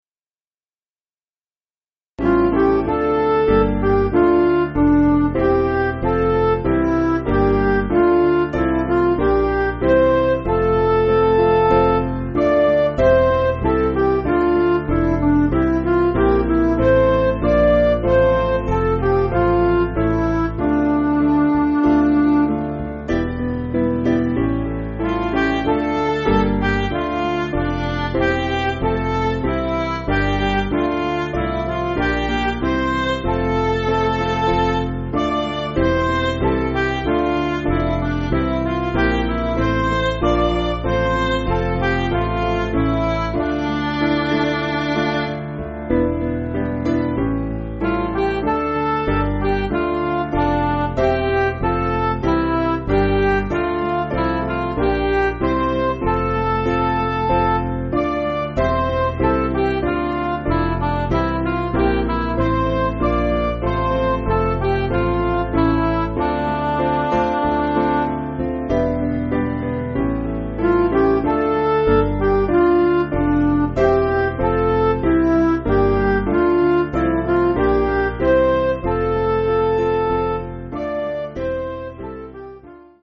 Piano & Instrumental
(CM)   8/Dm